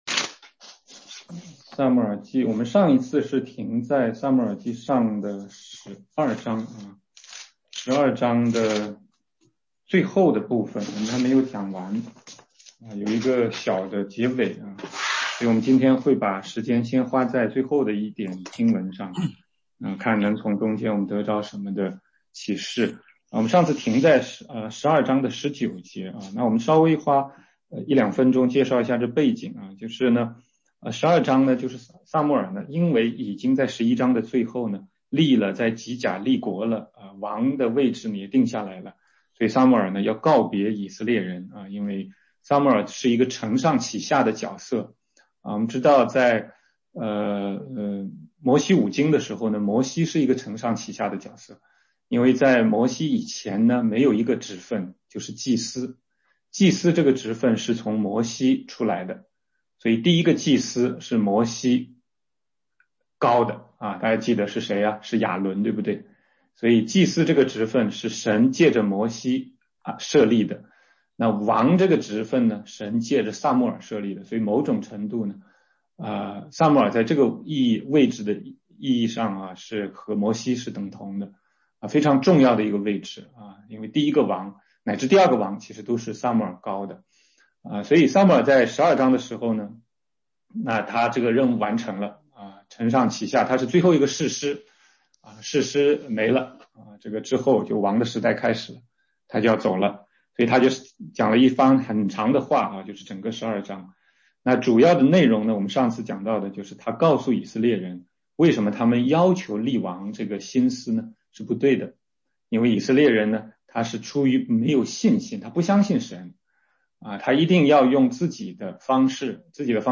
全中文查经